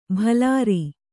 ♪ bhalāri